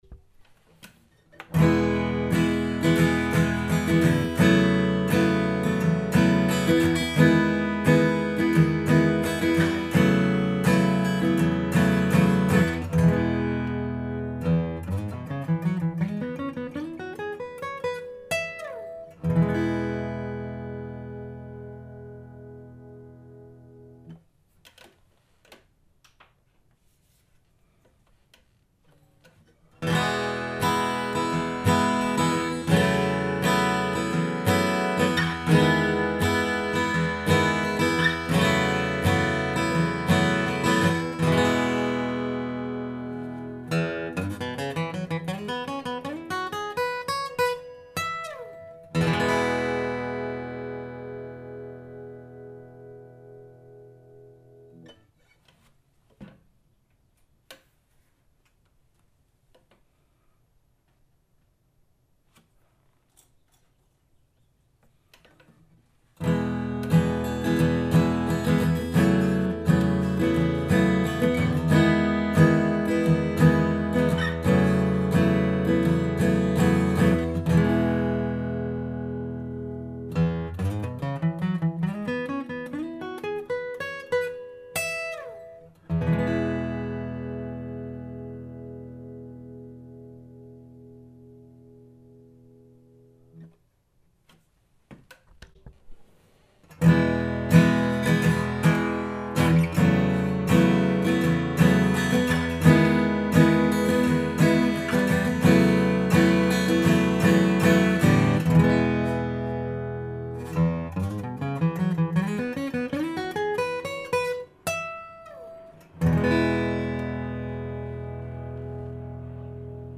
Просто пробежался по гамме кирок, которые у меня под рукой, для быстрого сравнения. Все играли спина к спине на одной гитаре, Lowden D32, в одной и той же мелодии. Мелодия требует некоторого движения через струны, а также включает выбранные триплеты для проверки контроля.
Blue Chip TP-1R 60 - очень плавный, хороший контроль, без шума, очень хороший объем и довольно яркие тона.
John Pearse Fast Turtle 2.5 - Плавный, очень низкий уровень громкости, небольшой динамический диапазон, отличный контроль, мало шума или вообще ничего.
Ultex 1.1 4 - Хороший контроль, хороший объем и динамика, темные, почти унылые тона.
Клейтон Делрин 1.26 - хорошая громкость, контроль, динамика и от нейтрального до темного тона.
D'Andrea Pro Plec 1.5 Modifie d - Хороший контроль, высокая громкость, отсутствие шума, отличная динамика с нейтральным или ярким тоном.